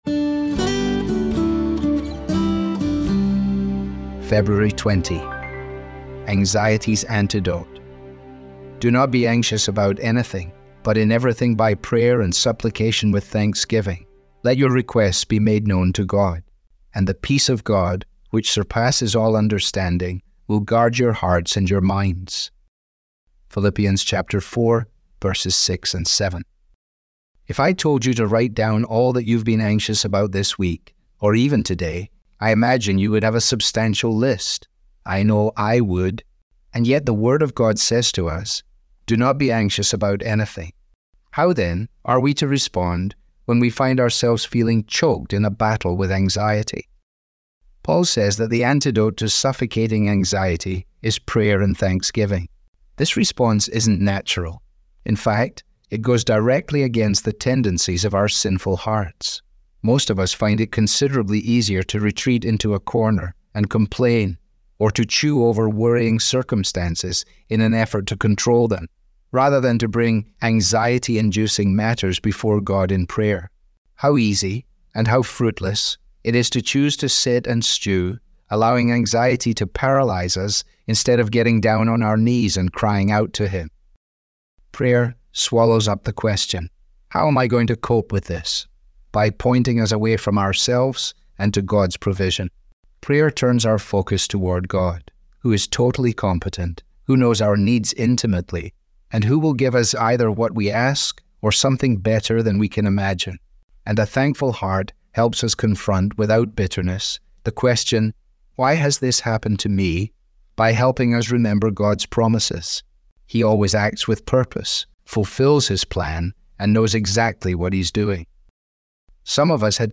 Audio was digitally created by Truth For Life with permission.